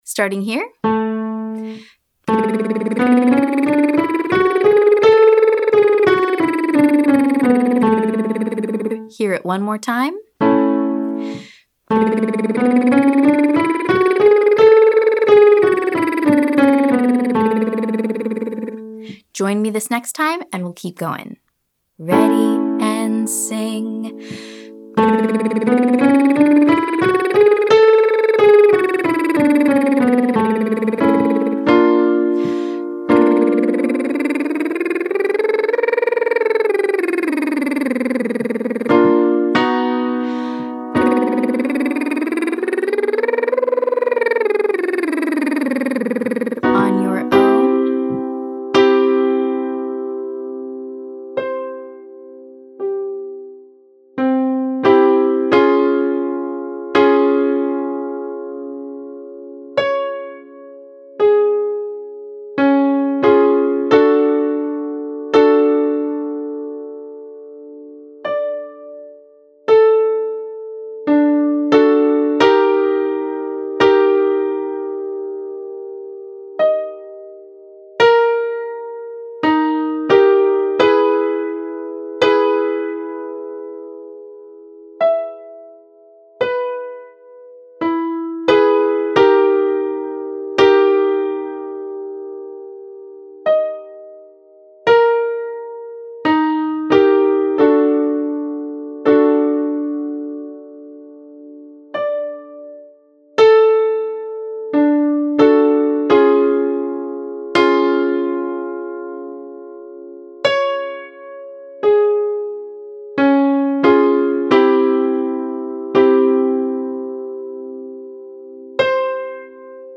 Welcome and Warmup
Before we jump in, let’s prepare with a gentle SOVT warmup.
Choose your favorite, and we’ll do one quick warmup on the major scale, ascending and descending.
Exercise: SOVT warmup on the full major scale (ascending and descending).